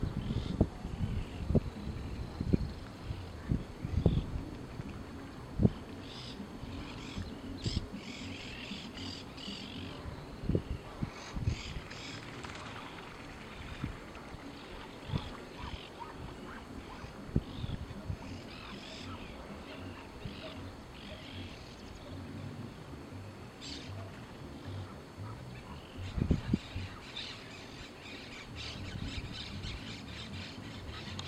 Monk Parakeet (Myiopsitta monachus)
Location or protected area: Perilago Termas de Río -hondo
Condition: Wild
Certainty: Recorded vocal